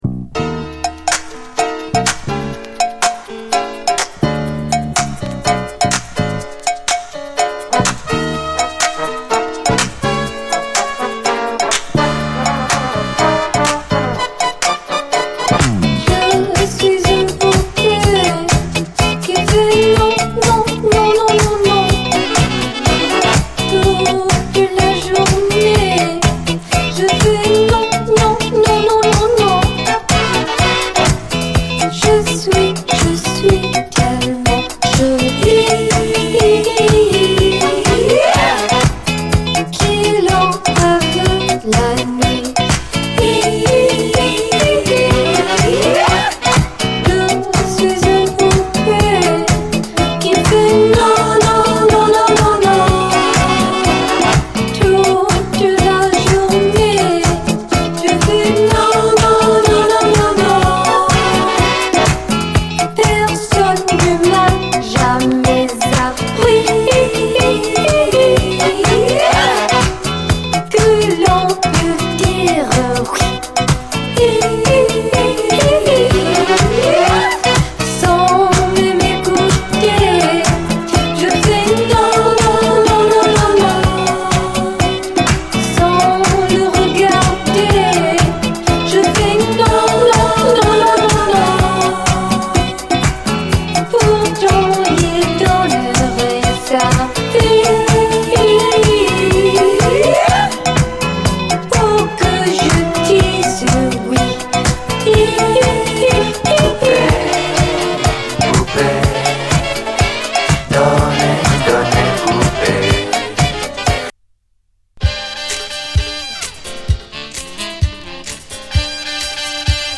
DISCO, 80's～ ROCK, ROCK, FUNK-A-LATINA, 7INCH